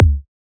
edm-kick-07.wav